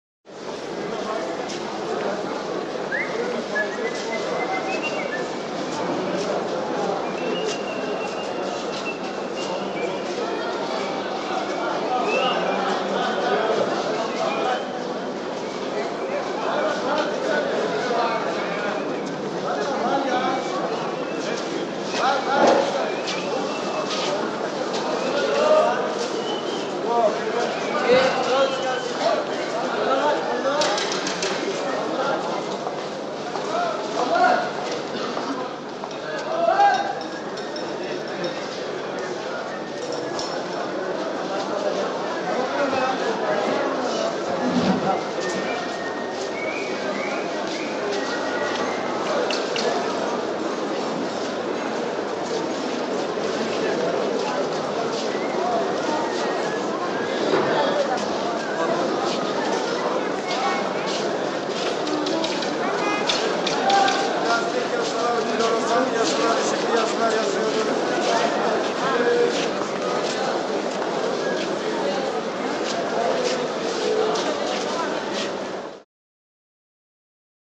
Market; Arabic; Arabic Shopping Arcade Atmosphere. Chatter, Footsteps, Whistling In Part And Background Noises. All With Echo.